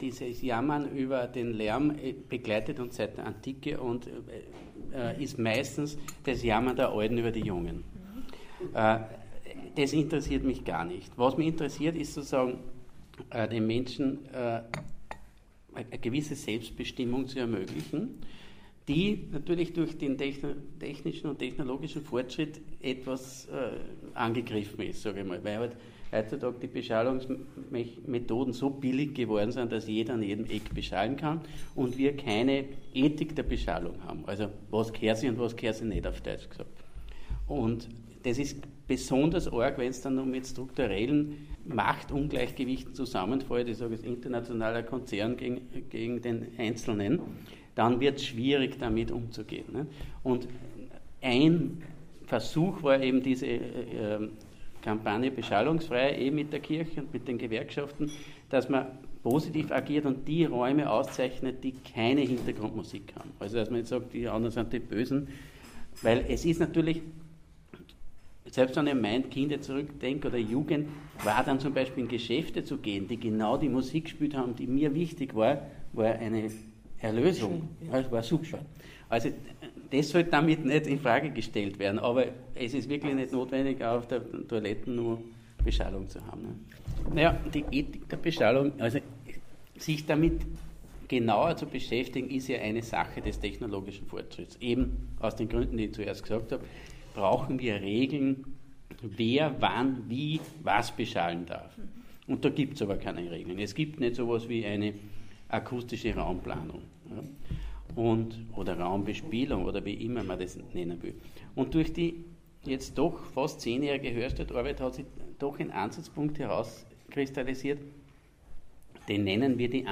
Die lebendige Diskussion geben auch die Hörbeispiele wider.